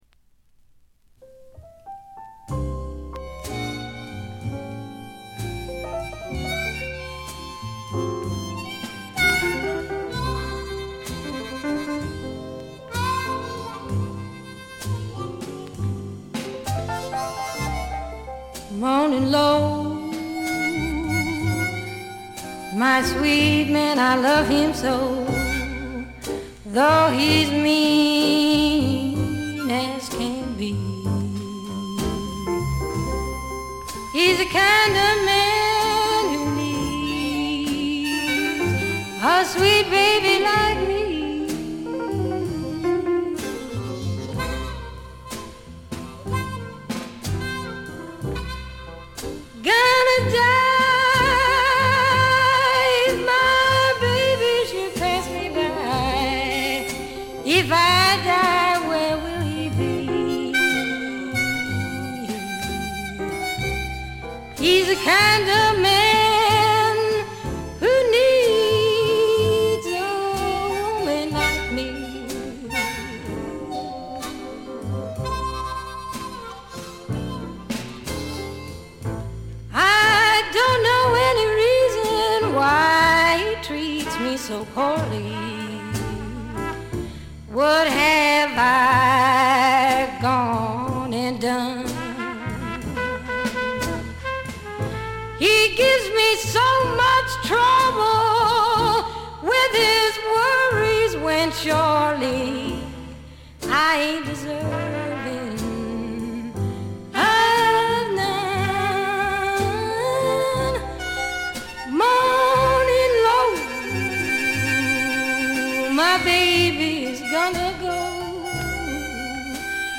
微細なノイズ感のみ。
ここでの彼女はみずからギターを弾きながら歌う渋い女性ブルースシンガーという側面を見せてくれます。
激渋アコースティック・ブルースにオールドジャズやR&Bのアレンジが施されたサウンド作りもいい感じですね。
試聴曲は現品からの取り込み音源です。